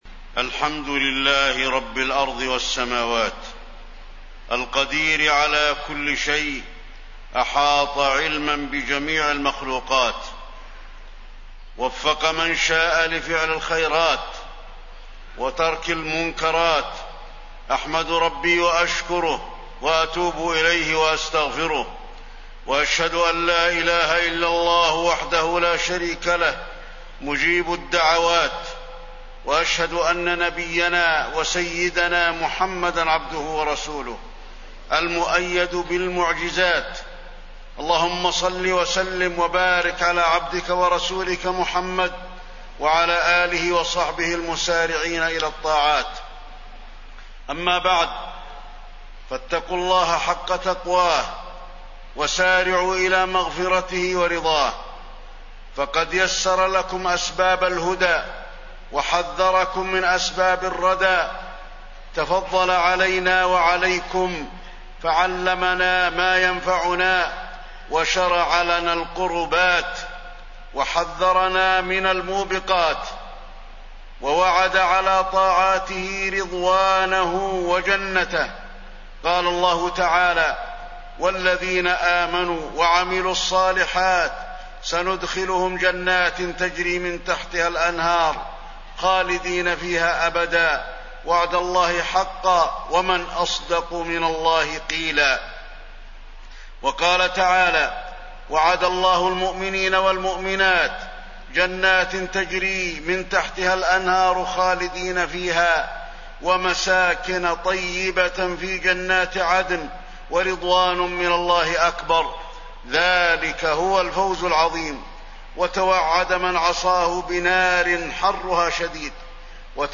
تاريخ النشر ١٧ رمضان ١٤٣١ هـ المكان: المسجد النبوي الشيخ: فضيلة الشيخ د. علي بن عبدالرحمن الحذيفي فضيلة الشيخ د. علي بن عبدالرحمن الحذيفي رمضان شهر القرآن